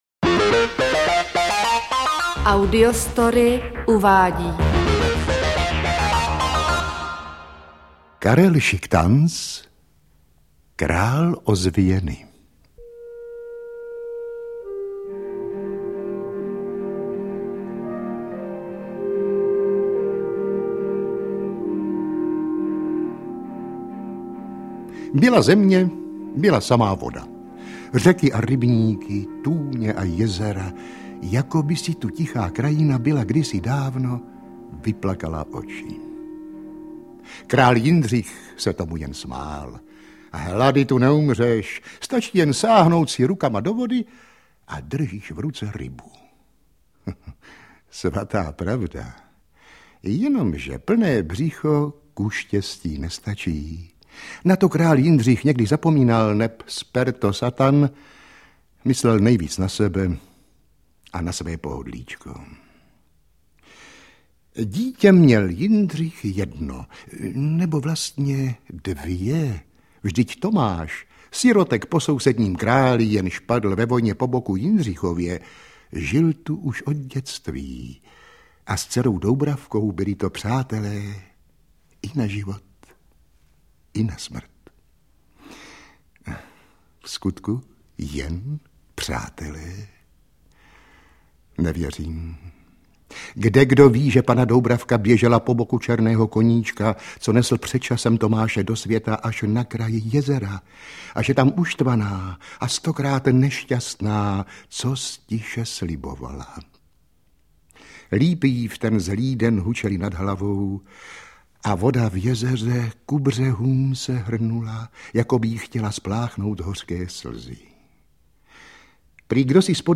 Interpret:  Eduard Cupák
děti a mládež / předškoláci / pohádky a legendy
AudioKniha ke stažení, 1 x mp3, délka 43 min., velikost 39,7 MB, česky